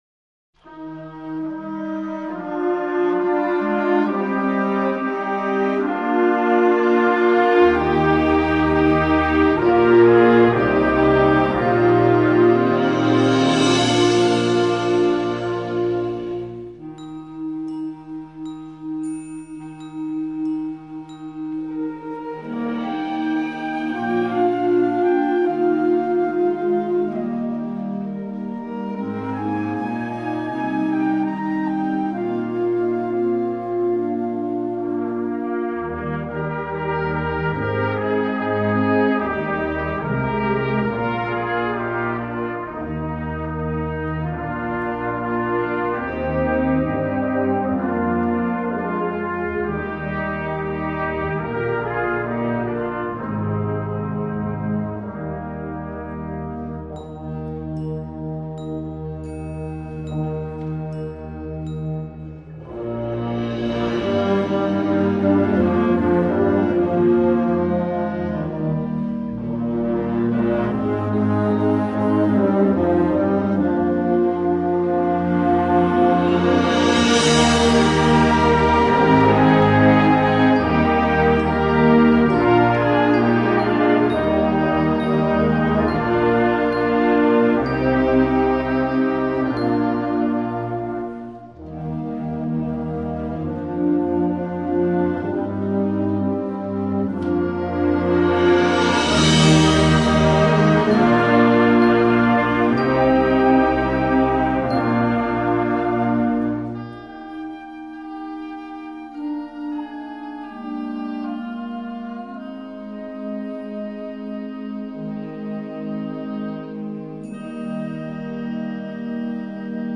Concert Band
Dutch Folk Songs
a piece that combines both lyrical and upbeat sections